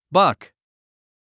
発音
bʌ́k　バァック
buck.mp3